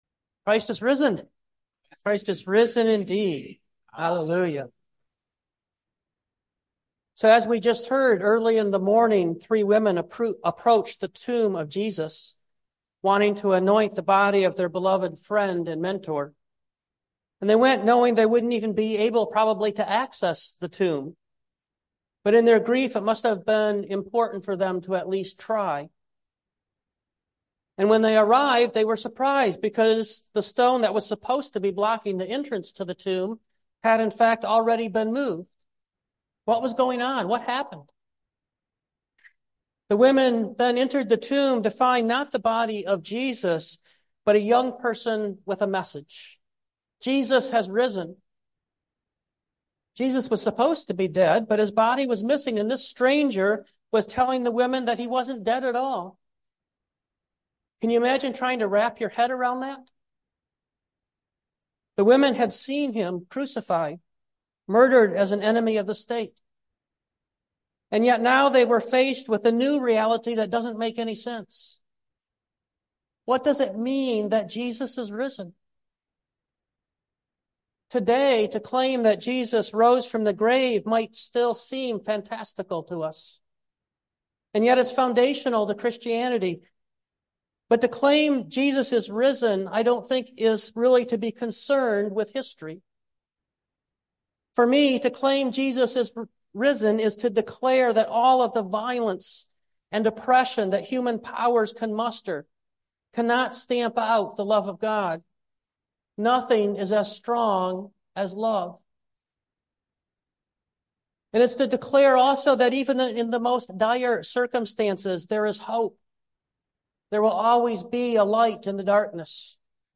2024 Leaving the Tomb Behind Preacher